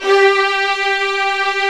Index of /90_sSampleCDs/Roland LCDP13 String Sections/STR_Violins V/STR_Vls8 Agitato